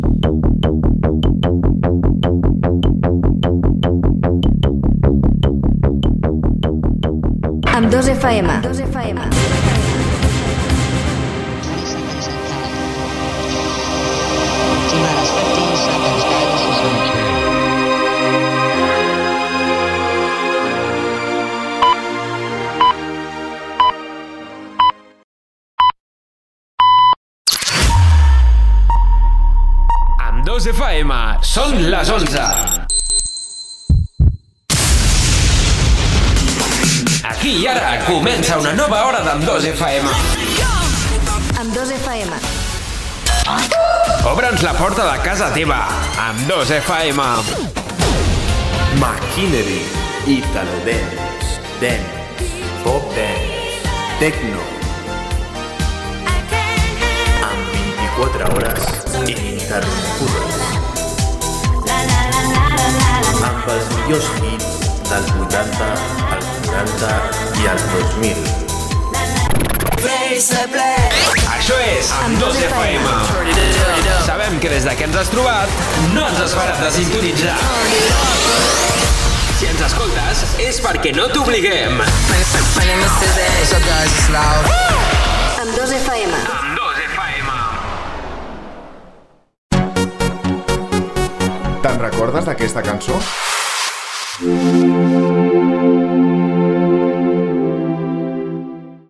0efb37117c30c28b4360ae12c93762c48f155668.mp3 Títol Amb2 FM Emissora Amb2 FM Titularitat Tercer sector Tercer sector Musical Descripció Indicatiu, hora, promoció de l'emissora.